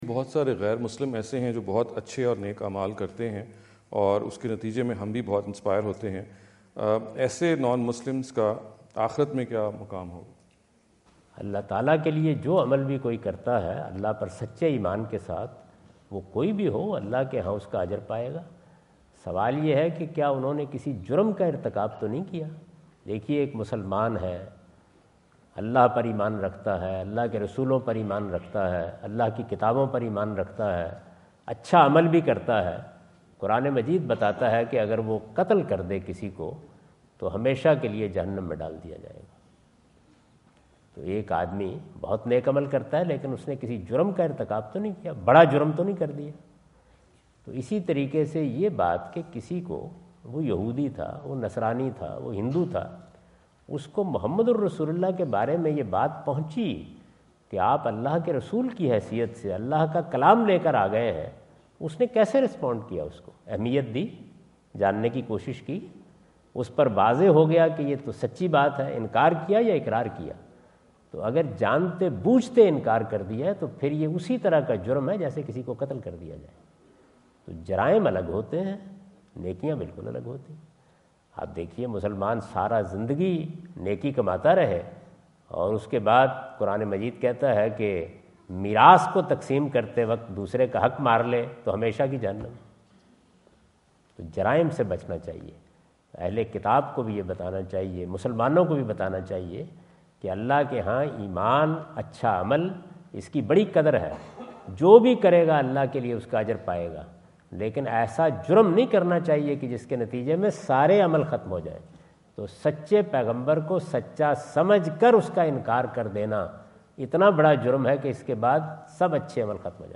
Category: English Subtitled / Questions_Answers /
Javed Ahmad Ghamidi answer the question about "Pious Non-Muslims and the Hereafter" asked at The University of Houston, Houston Texas on November 05,2017.